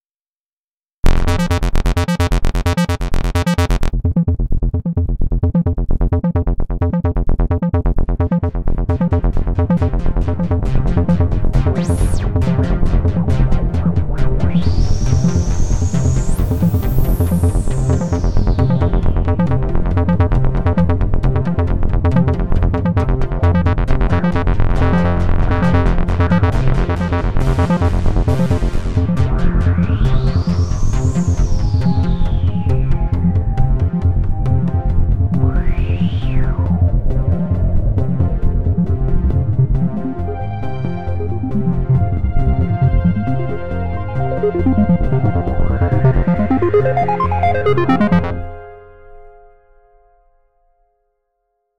THING是一个32个振荡器和30个FX处理器以及滤波器合成器。
-Polyphonic Synth-
基于FM，加法和减法合成器。